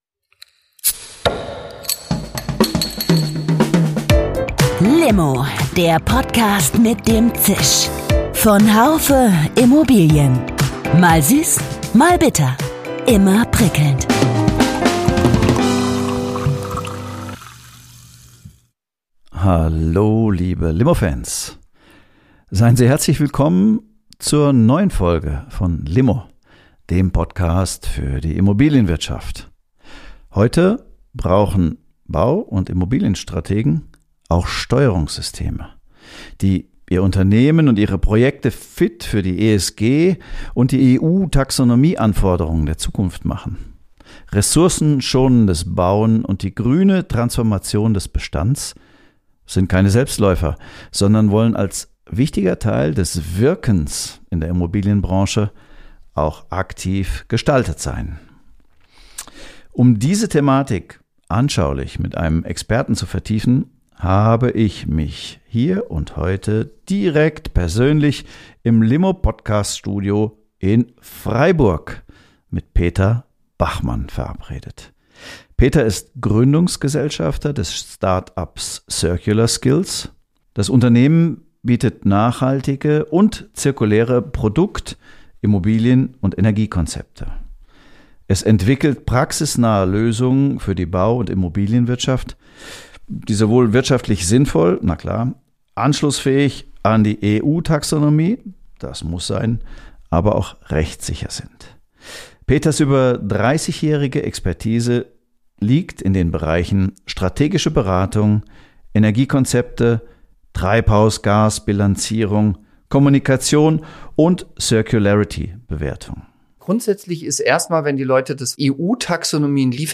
Ein Talk über strategische Beratung, Nachhaltigkeitsberichte, Bilanzierung und praktische Circularity-Bewertung.